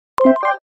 positive-beeps.mp3